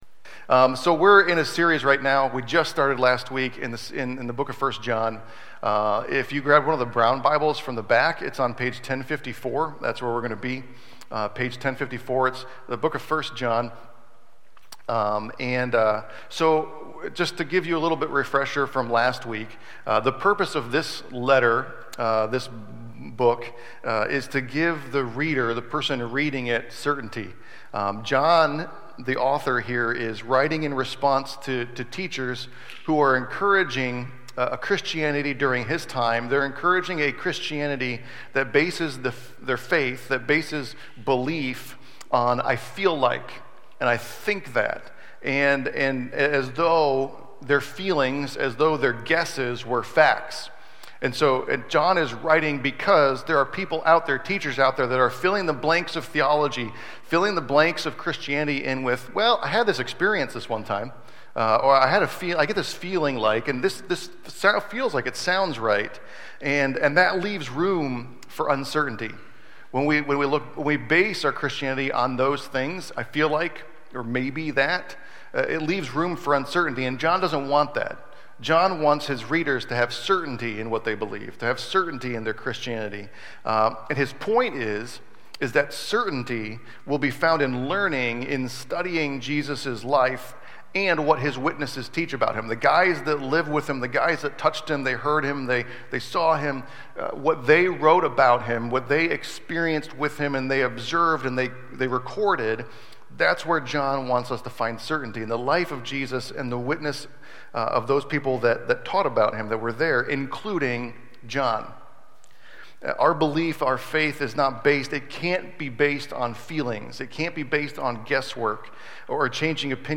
Service Type: Sunday AM Our belief and faith is not based, cannot be based on feelings or guesswork or changing opinions or mere experience.